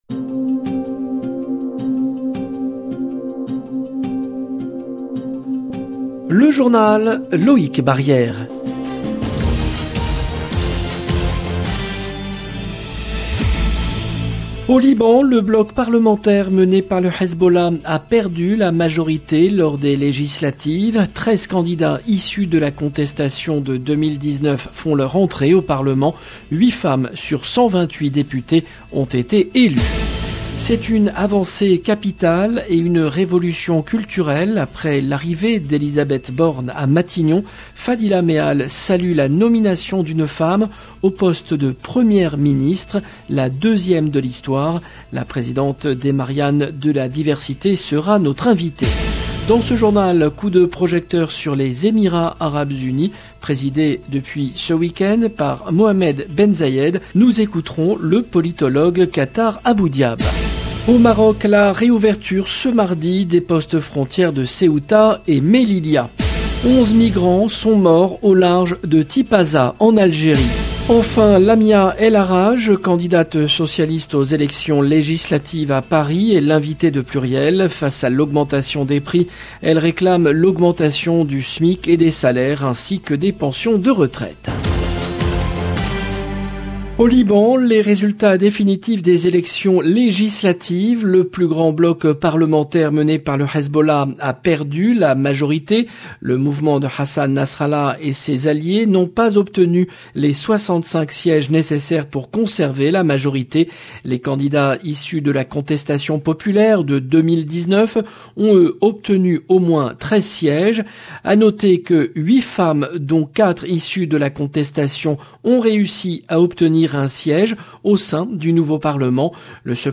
Journal présenté par